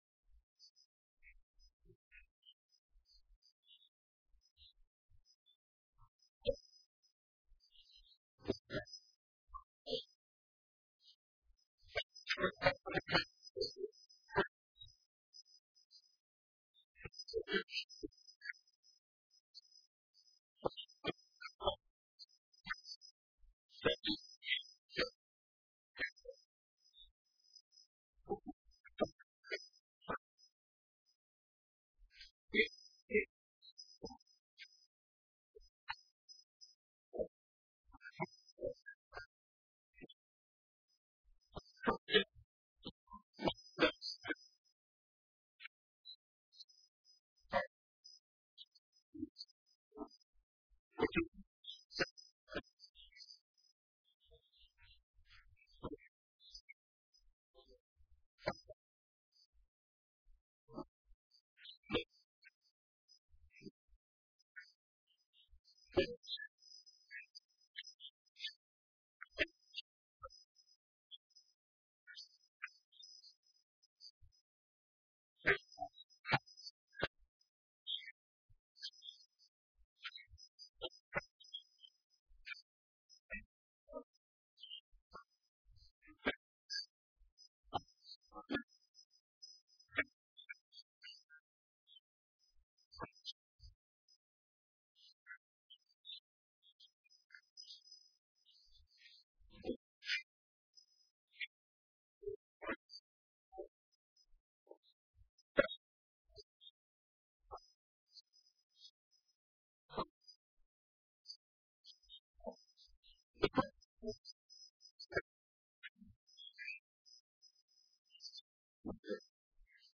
Online Sermons at St. Pauls